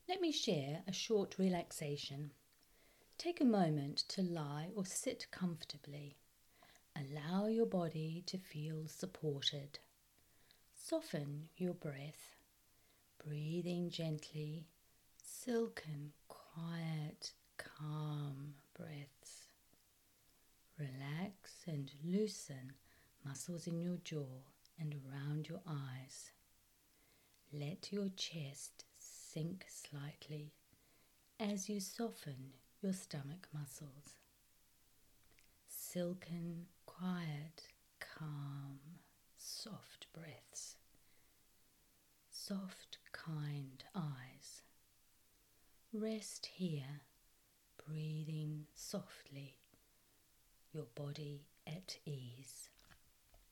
short-relaxation.mp3